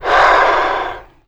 MONSTER_Breath_06_mono.wav